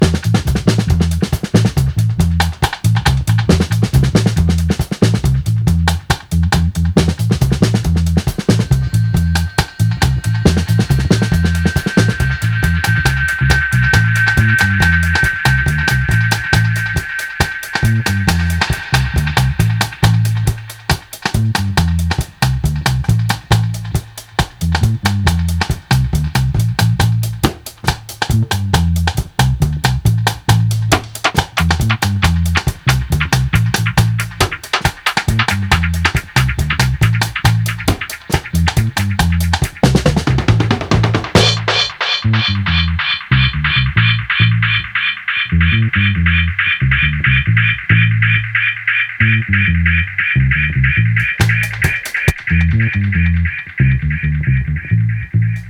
オールアナログにこだわり、すべての楽器を自分で演奏し、16 トラックのオープンリール MTR への録音